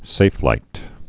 (sāflīt)